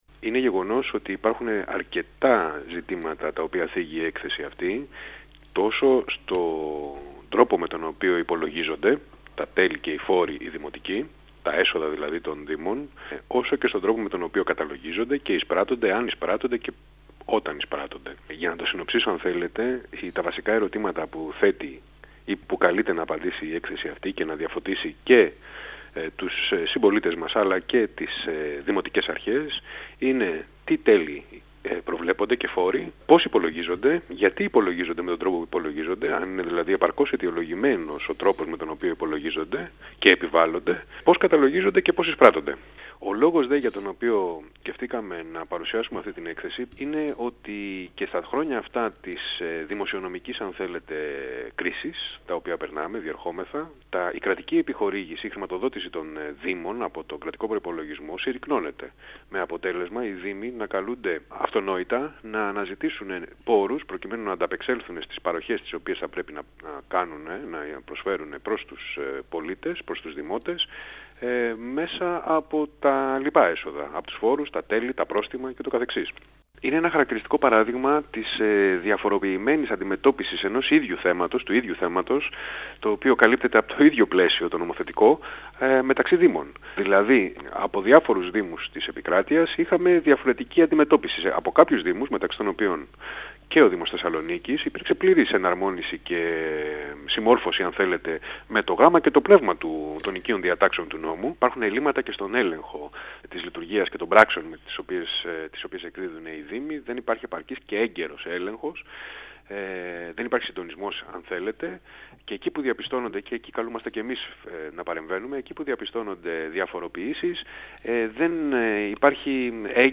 Ο Συνήγορος του Πολίτη, Ανδρέα Ποττάκη, στον 102FM του Ρ.Σ.Μ. της ΕΡΤ3
Συνέντευξη